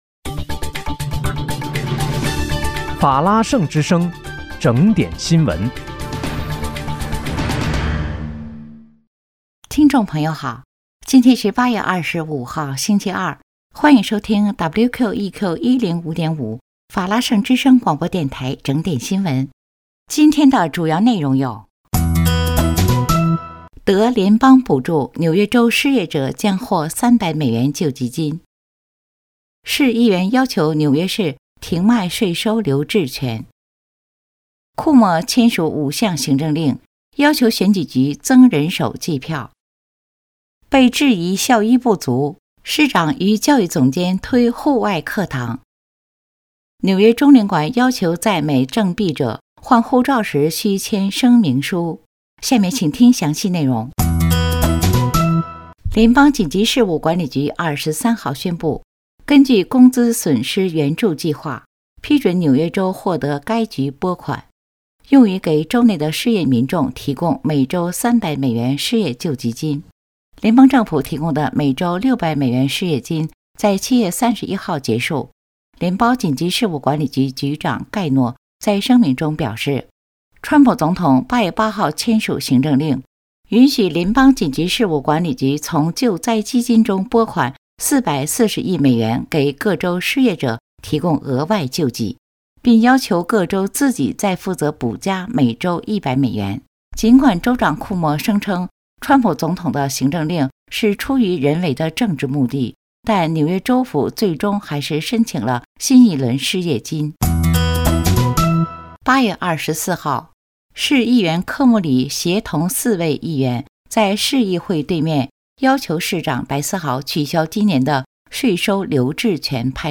8月25日（星期二）纽约整点新闻